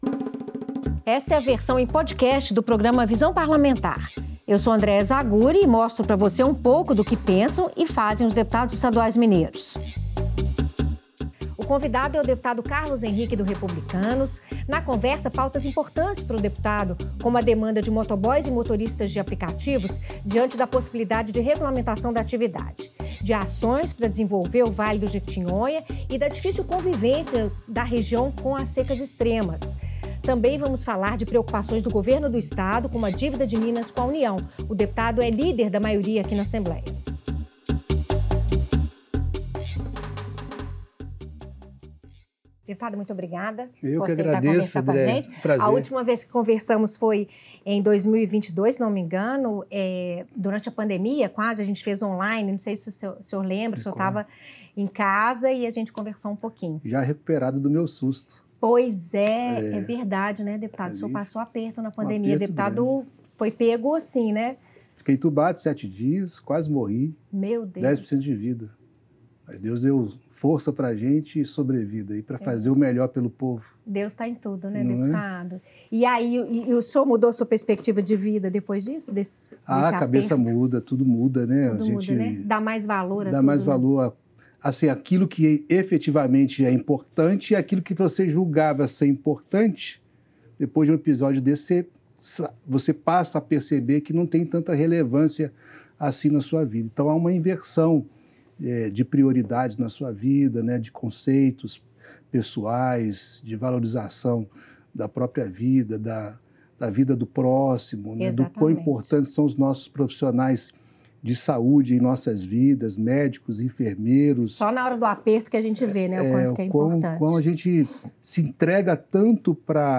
Em entrevista ao programa Visão Parlamentar, o deputado Carlos Henrique (Republicanos) fala sobre algumas demandas dos motoboys, categoria que participou de audiência pública na Assembleia e posicionou-se contra uma eventual regulamentação da atividade profissional. O parlamentar também avalia a exploração de lítio no Vale do Jequitinhonha, defende a diversificação econômica da região e pede mais investimentos na infraestrutura local. Carlos Henrique fala ainda sobre a dívida de Minas com a União e defende a realização de um amplo debate para solucionar a situação fiscal do Estado.